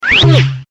warppipe.mp3